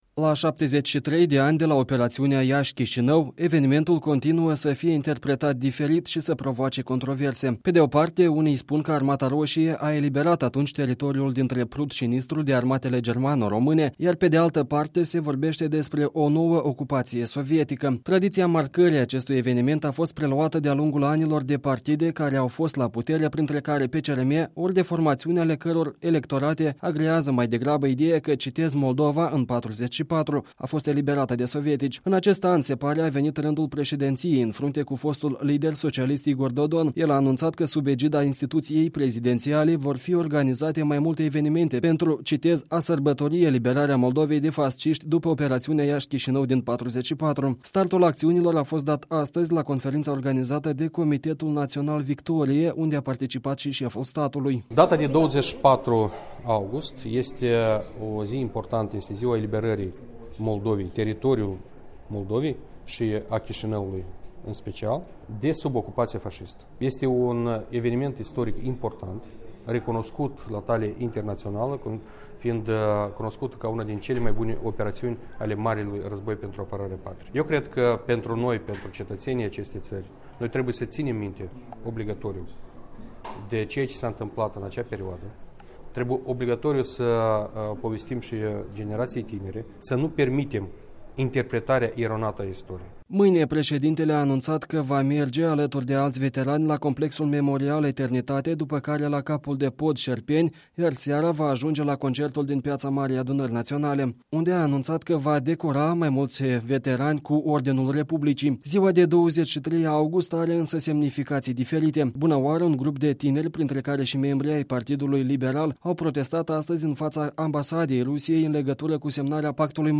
Despre manifestările organizate la Chișinău, o relatare